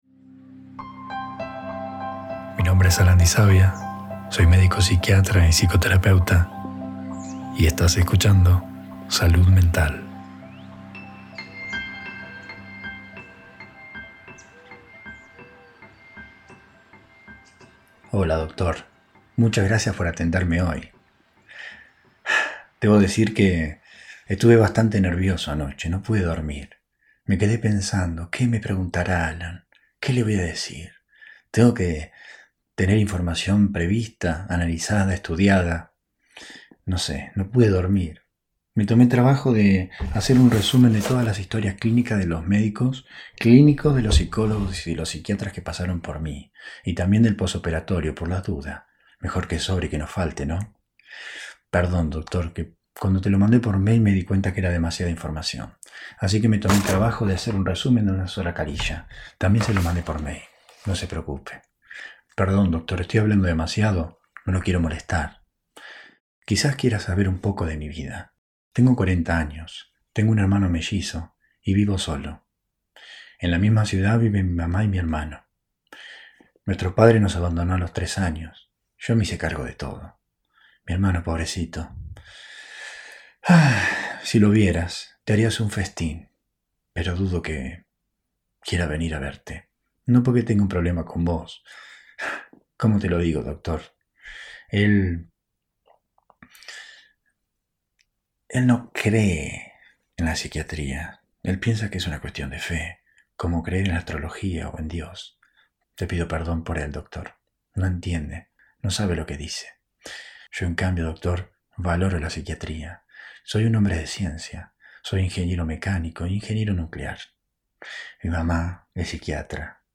Dramatización del perfil de Sobrecontrol subtipo excesivamente agradable